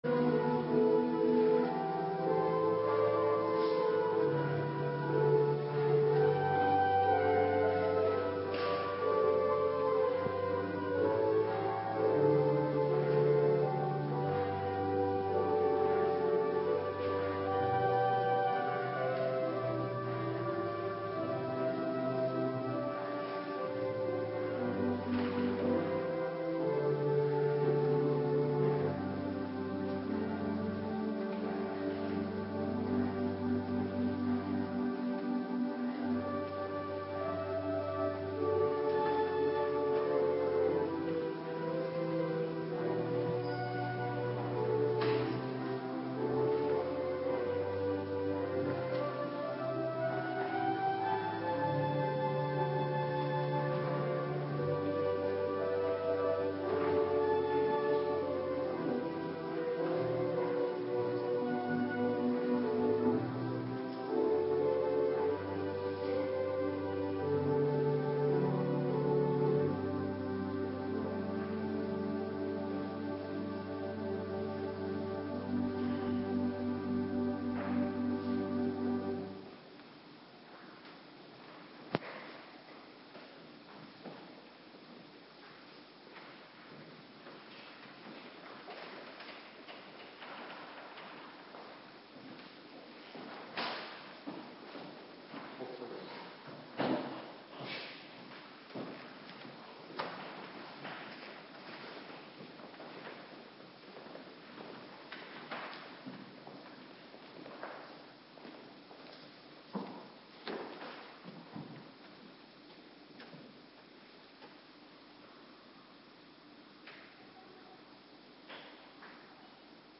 Woensdagavond Bijbellezing